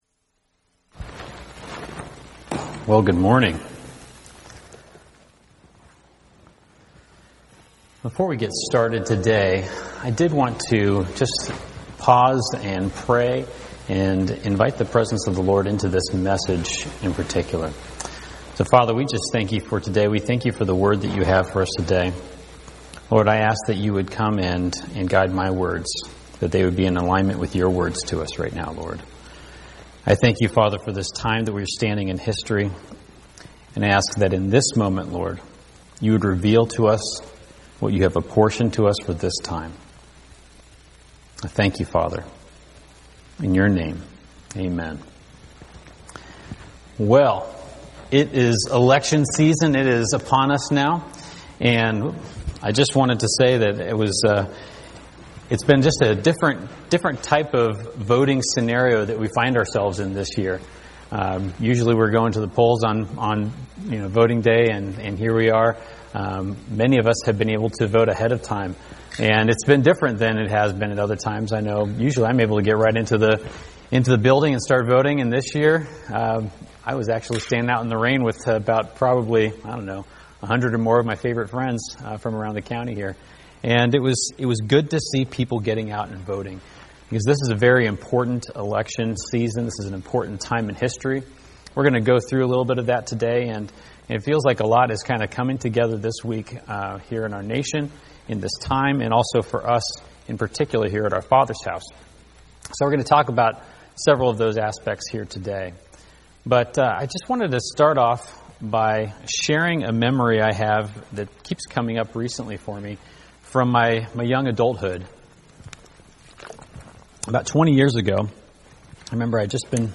preaches from Isaiah 58 and Proverbs 22 on aligning with God's purposes.